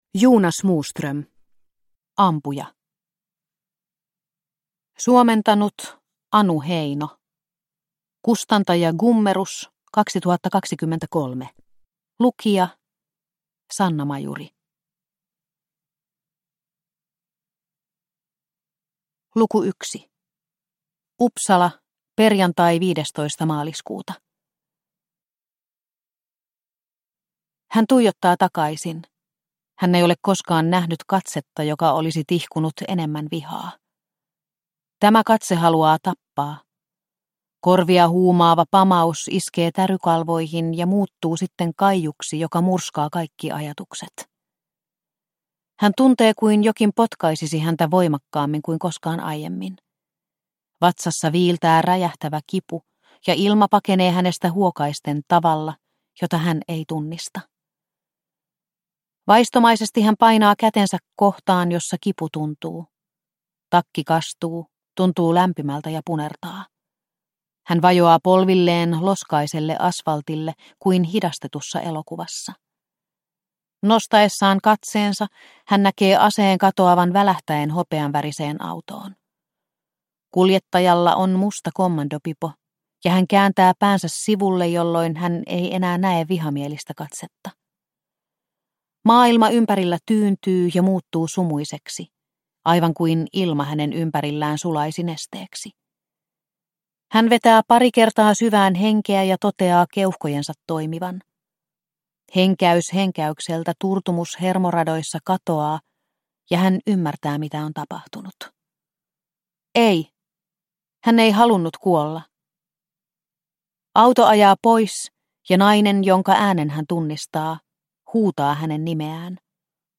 Ampuja – Ljudbok – Laddas ner